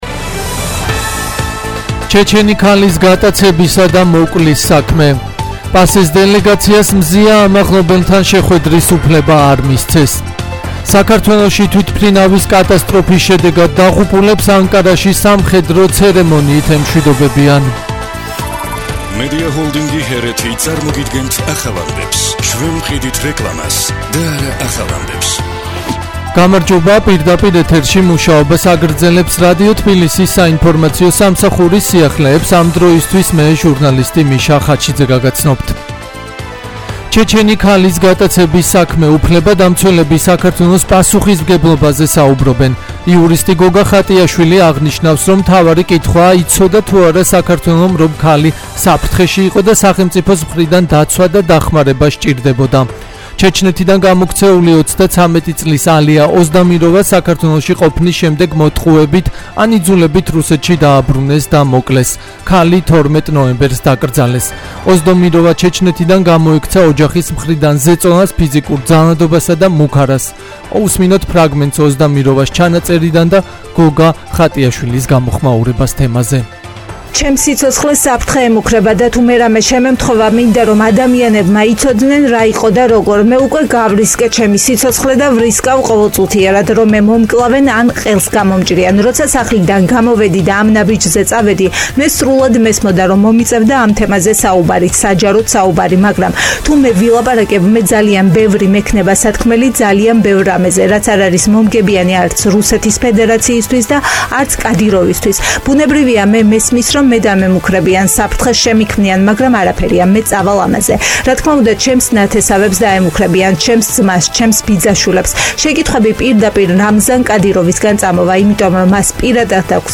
ახალი ამბები 13:00 საათზე